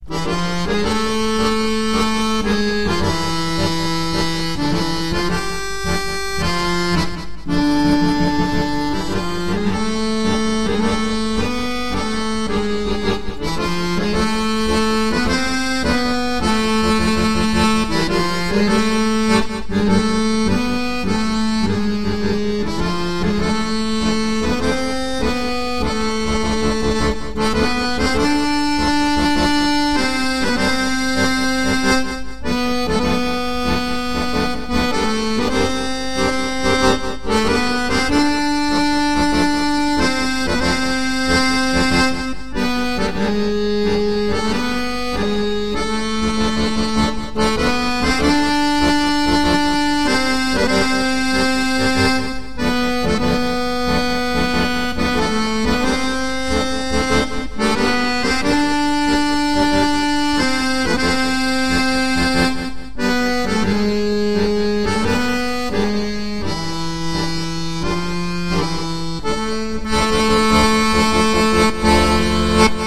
Hymn LSCZabiałcza
hymn.mp3